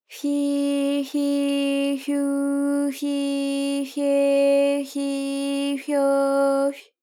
ALYS-DB-001-JPN - First Japanese UTAU vocal library of ALYS.
fyi_fyi_fyu_fyi_fye_fyi_fyo_fy.wav